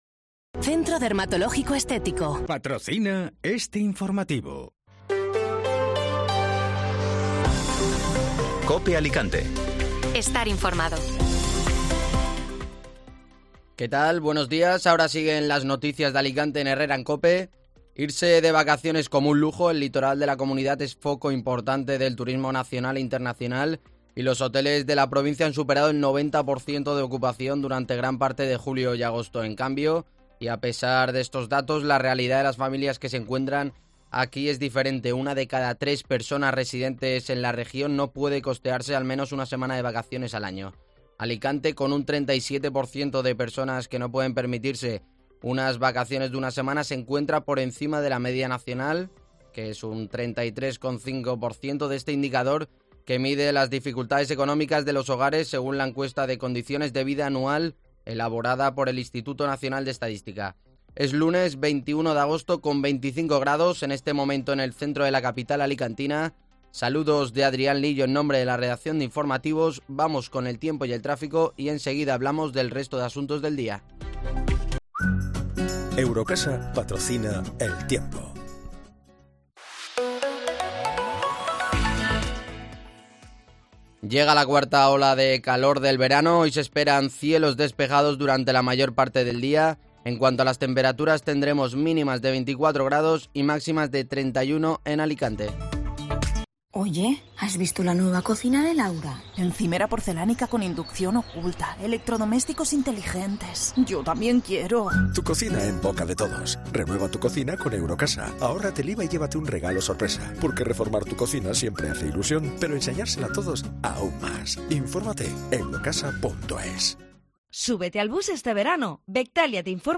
Informativo Matinal (Lunes 21 de Agosto)